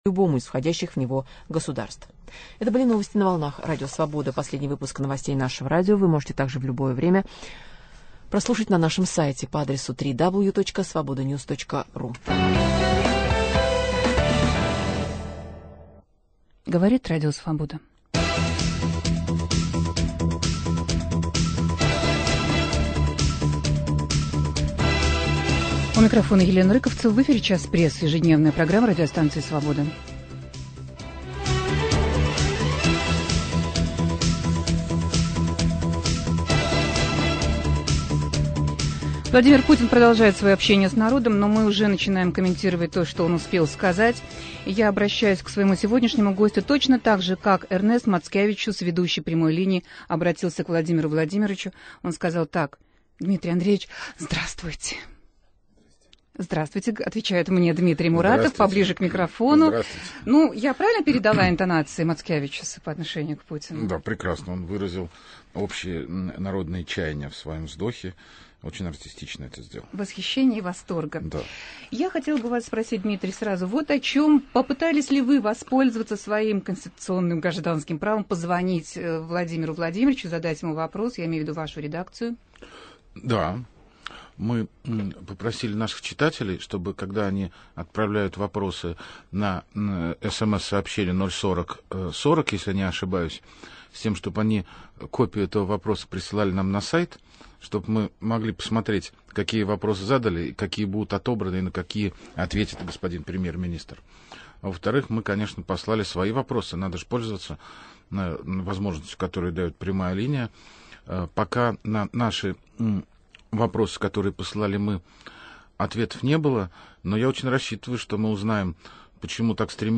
Говорит Путин, комментирует Муратов. "Прямую линию" Владимира Путина с народом смотрит в прямом эфире и тут же обсуждает со слушателями Радио Свобода главный редактор "Новой газеты" Дмитрий Муратов.